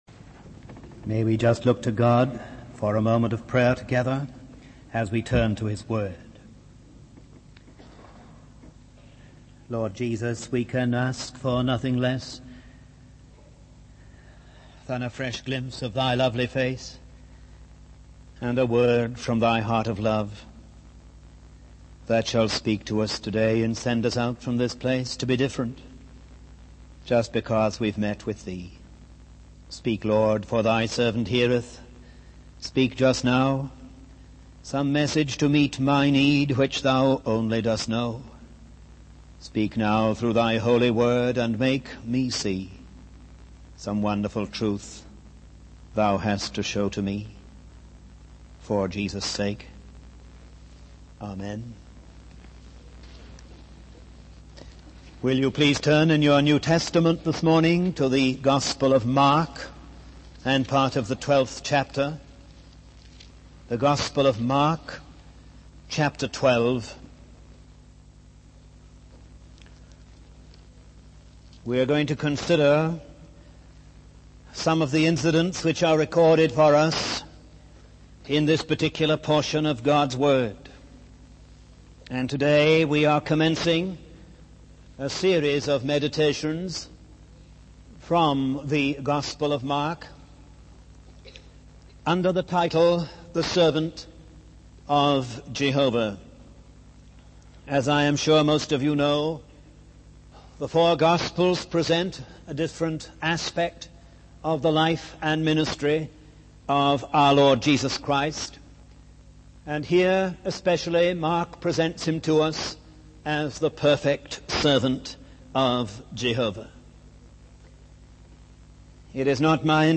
The sermon calls believers to reflect on their own responses to Jesus and the essence of their faith in light of his teachings.